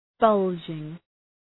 Shkrimi fonetik {‘bʌldʒıŋ}